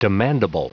Prononciation du mot demandable en anglais (fichier audio)
Prononciation du mot : demandable
demandable.wav